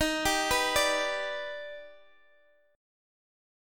Eb+M7 chord